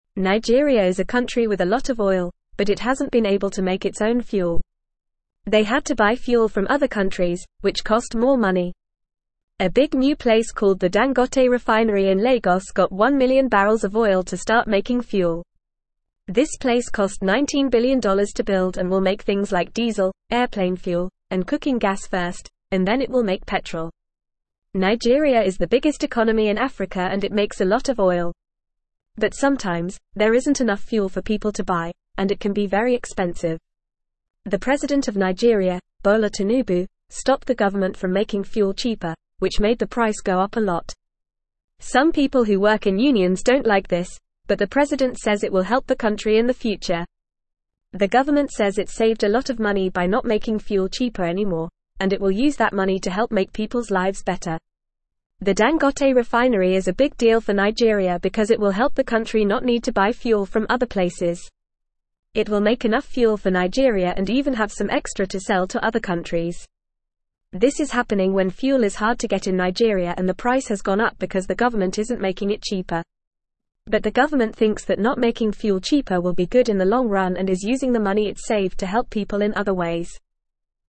Fast
English-Newsroom-Lower-Intermediate-FAST-Reading-Nigeria-Makes-Its-Own-Gas-to-Save-Money.mp3